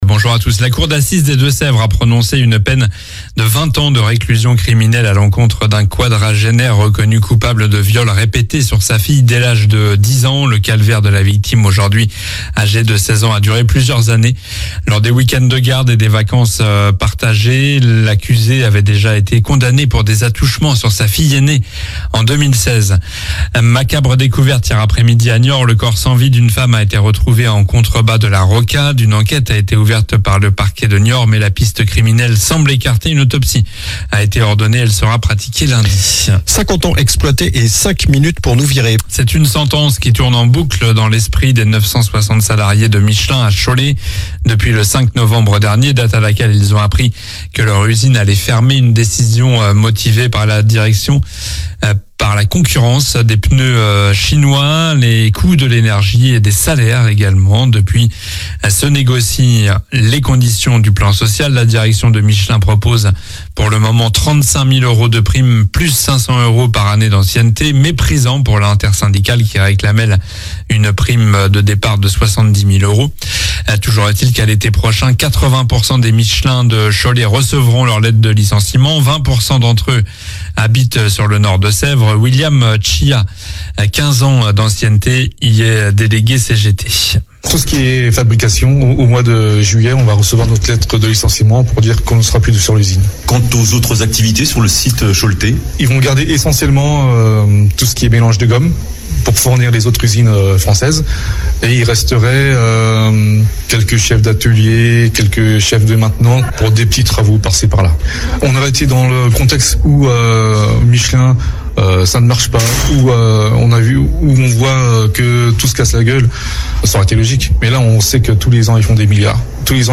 Journal du samedi 25 janvier (matin)